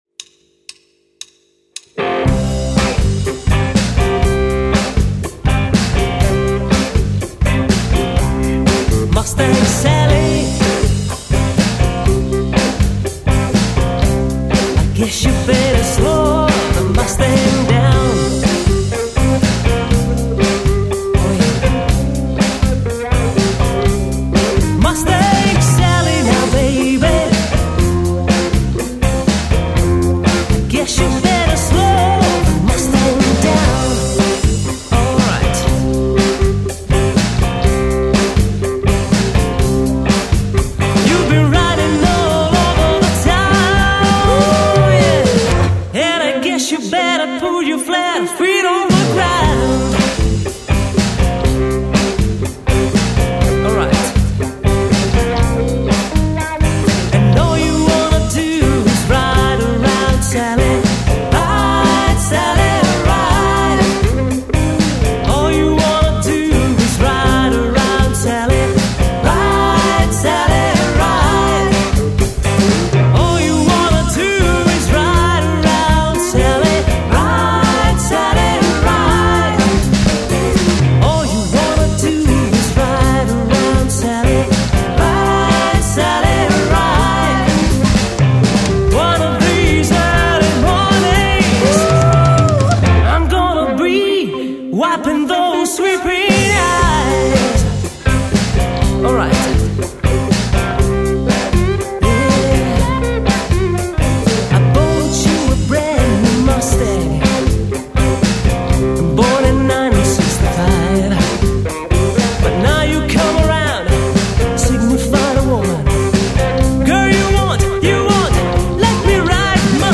performed entirely LIVE